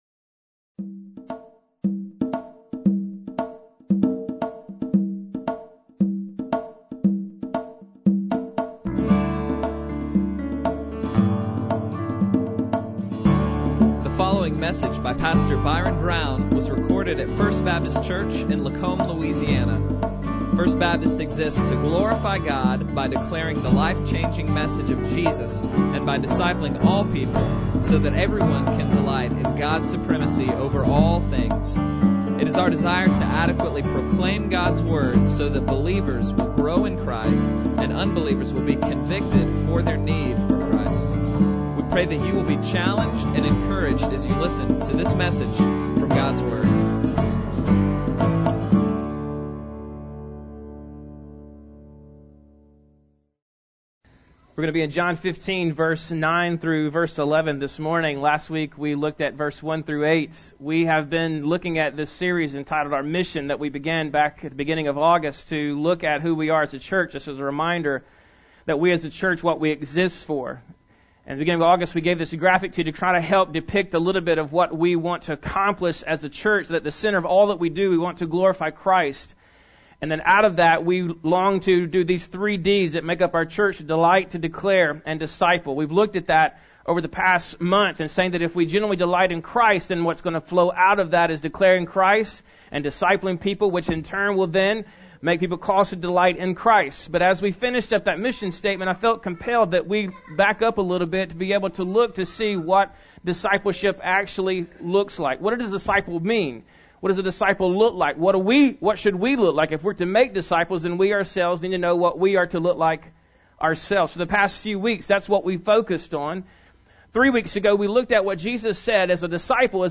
Bible Text: John 15:9-11 | Preacher